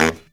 LOHITSAX01-L.wav